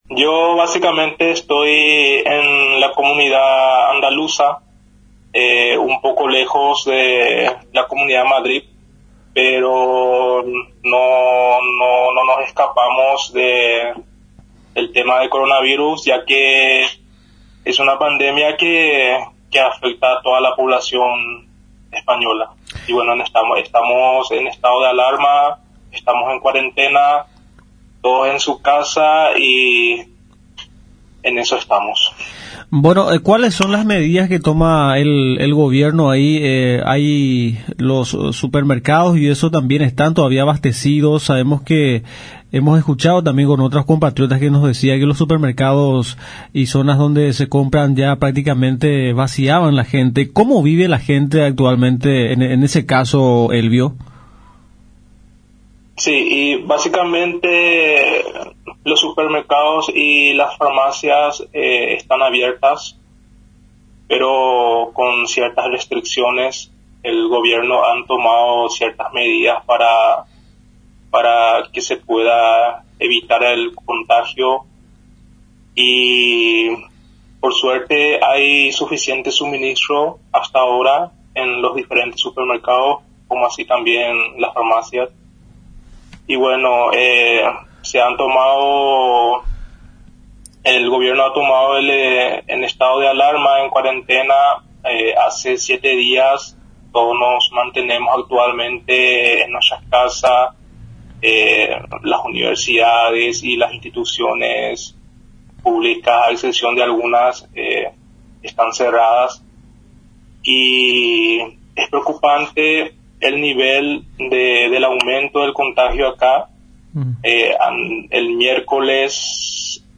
Relató, en entrevista a Radio Nacional San Pedro, la situación por la que está atravesando el país europeo, ante la propagación del coronavirus.